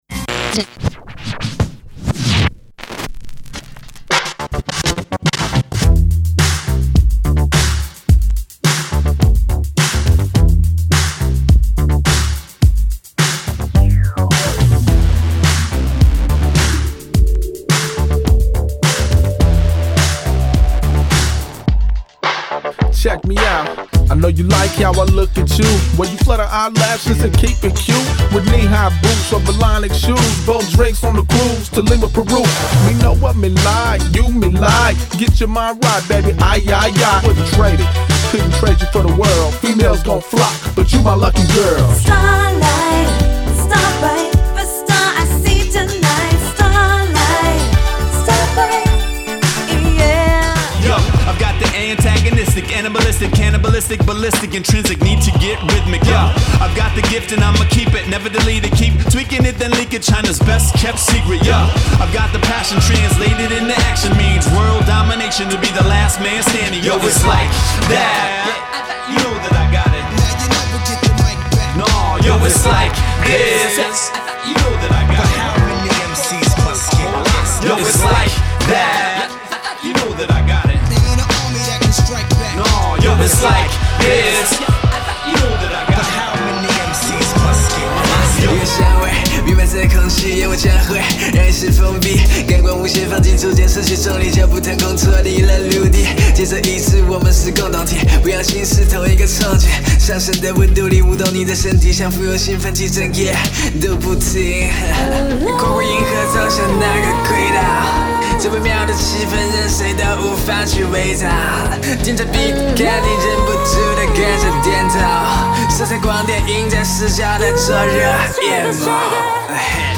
HIP HOP PRODUCTION DEMO